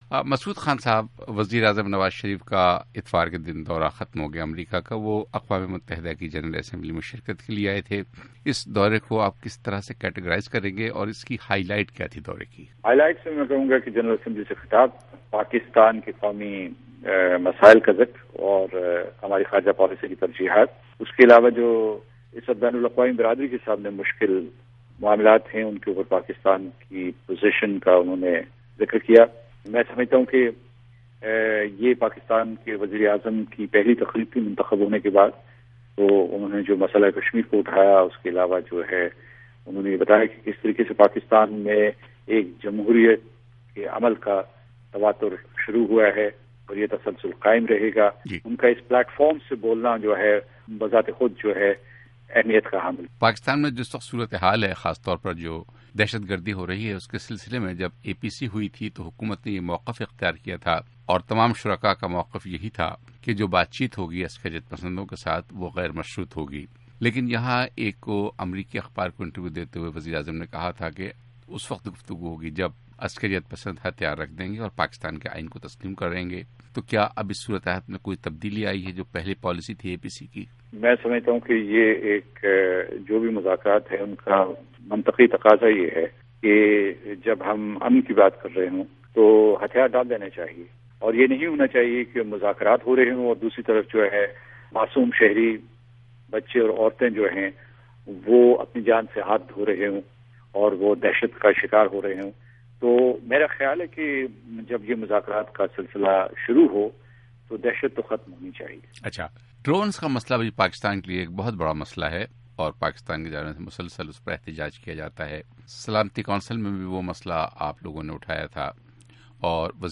اقوام متحدہ میں پاکستان کے مستقل مندوب، مسعود خان سے بات چیت